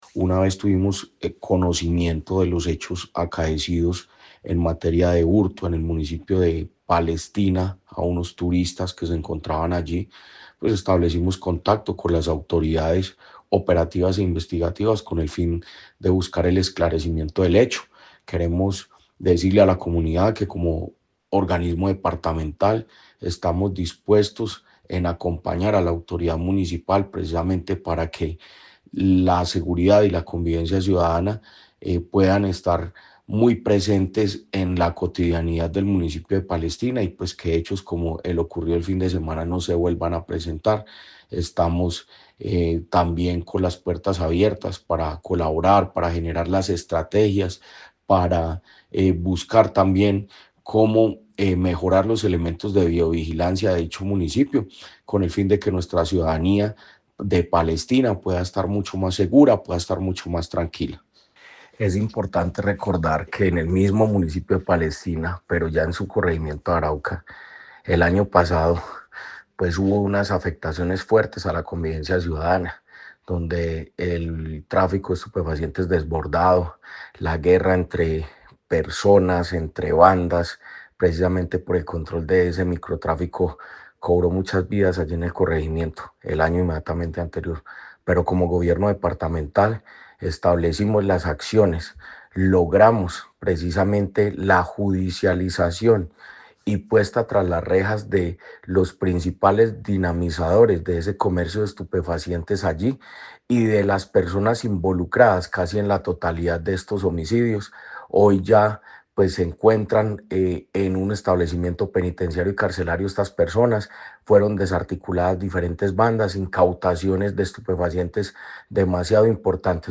Jorge Andrés Gómez Escudero, secretario de Gobierno de Caldas.
Jorge-Andres-Gomez-Escudero-secretario-de-Gobierno-de-Caldas.mp3